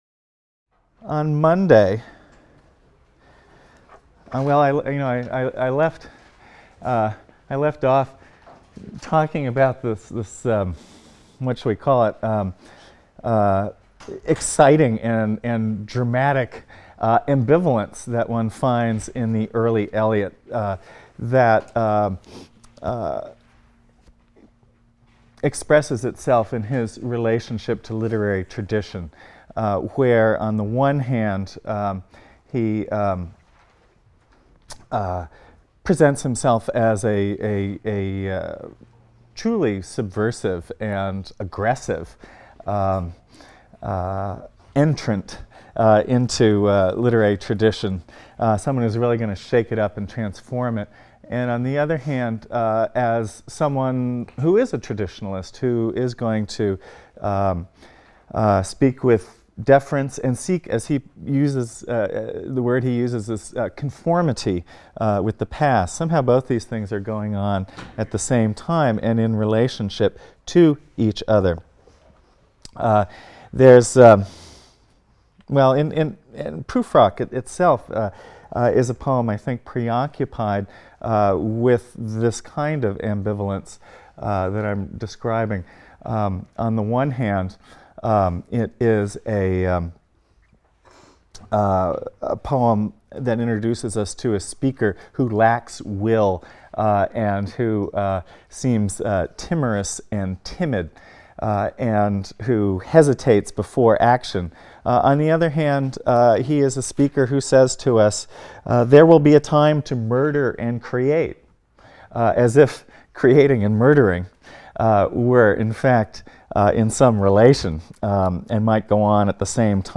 ENGL 310 - Lecture 11 - T.S. Eliot (cont.)